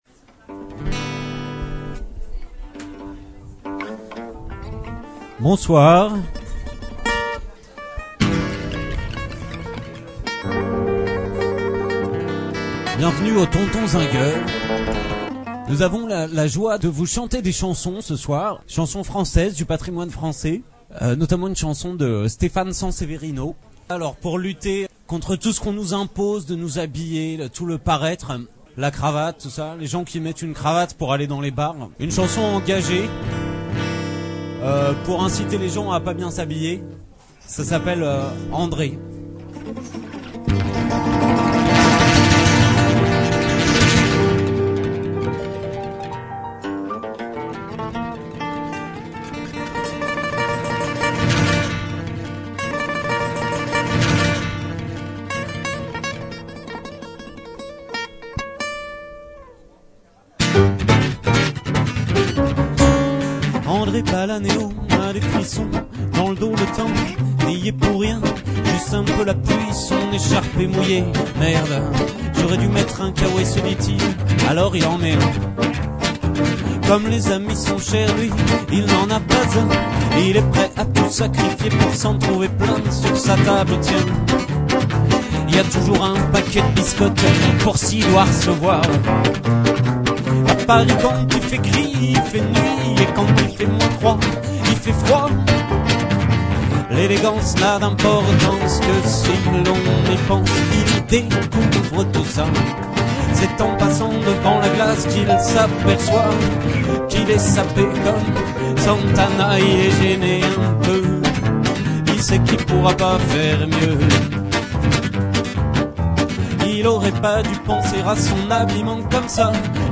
Gm Allegro
live